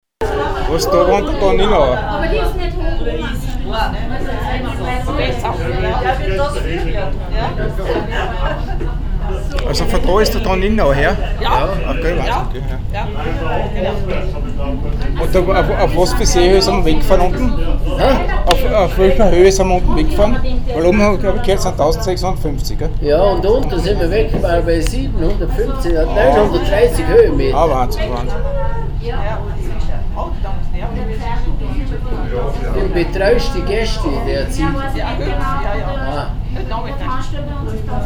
Unsere Gondelfahrt mit der Seilbahn Bezau.
Gondelfahrt Bezau.MP3